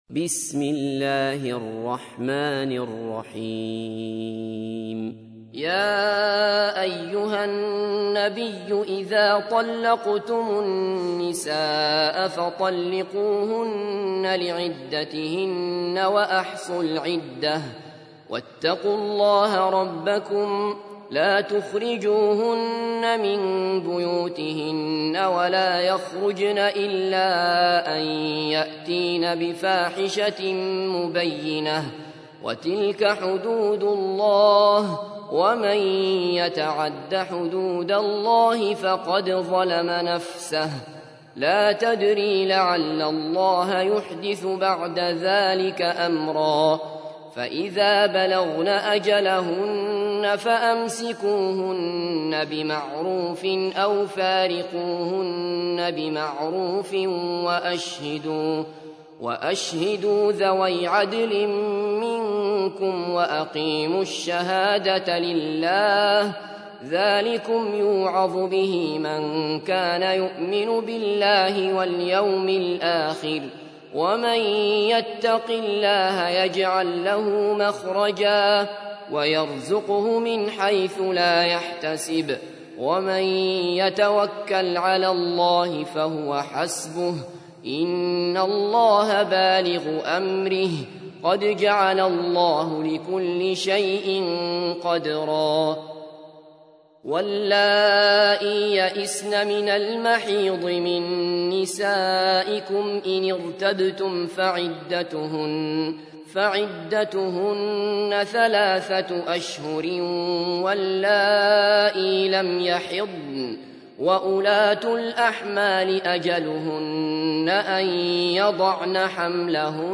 تحميل : 65. سورة الطلاق / القارئ عبد الله بصفر / القرآن الكريم / موقع يا حسين